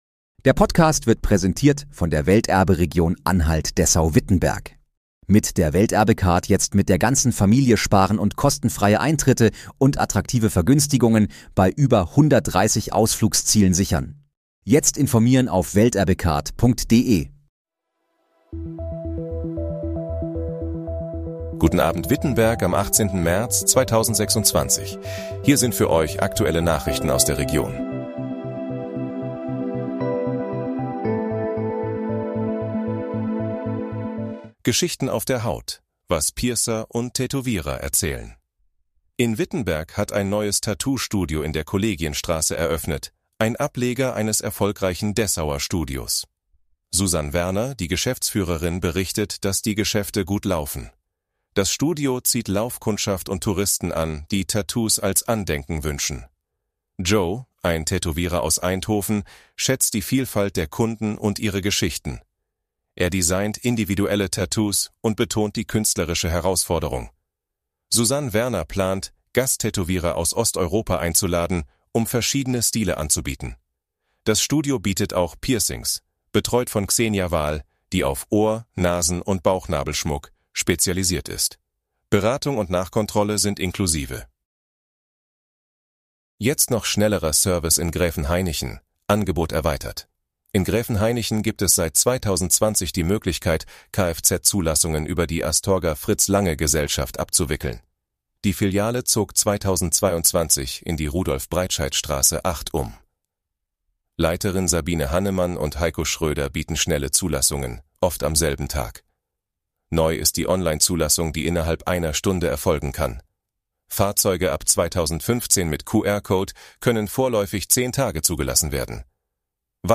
Guten Abend, Wittenberg: Aktuelle Nachrichten vom 18.03.2026, erstellt mit KI-Unterstützung
Nachrichten